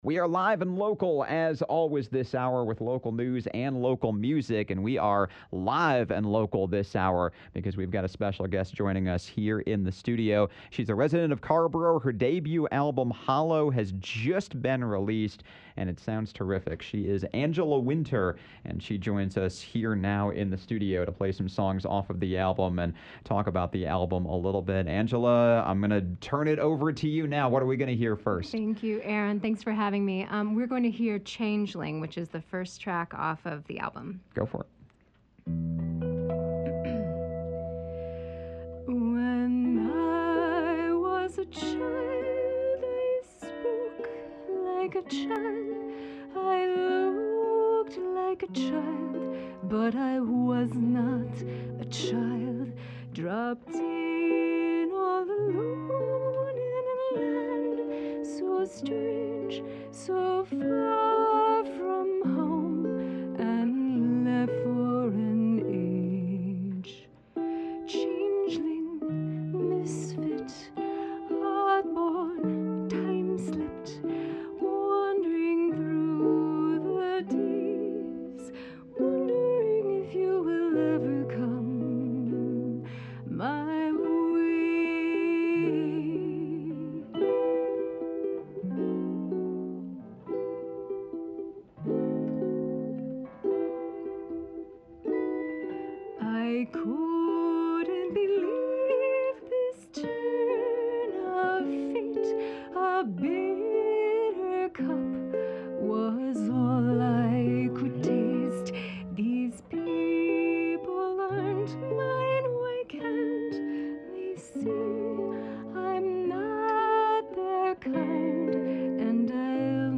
guitarist
to discuss her journey and play a few tracks off the album.